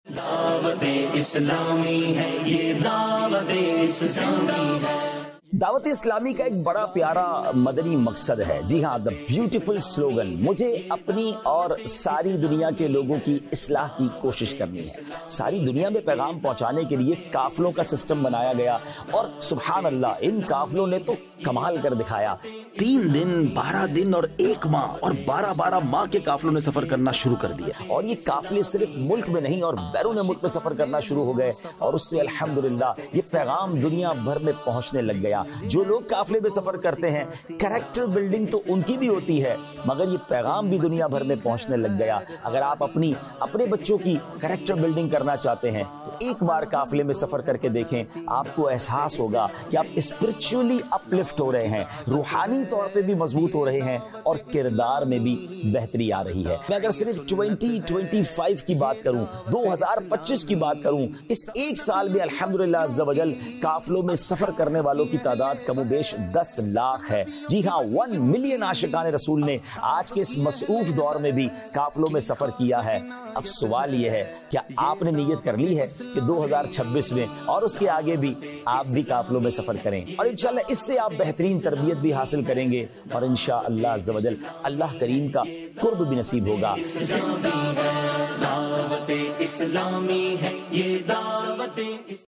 Qafilah | Department of Dawateislami | Documentary 2026
Vocalist